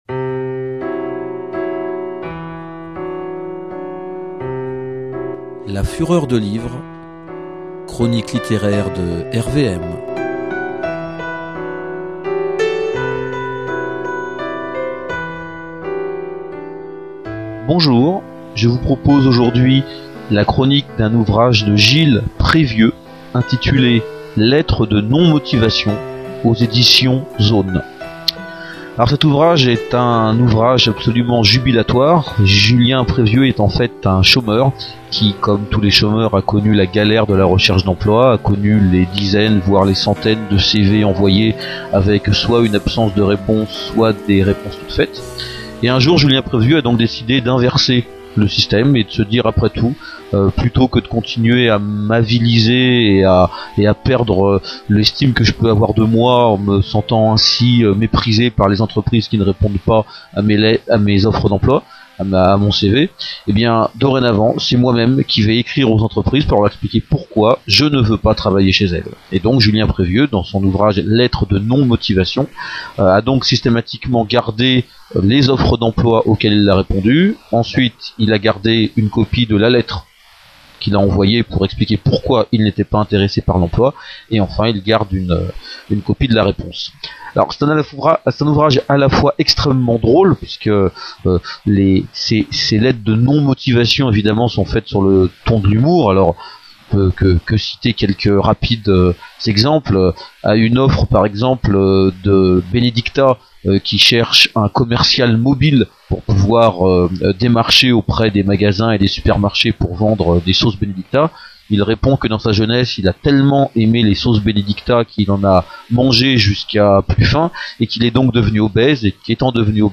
La fureur de livre est une émission hebdomadaire de radio
diffusée sur RVM 93.7 FM (radio associative du valois, dans l'Oise).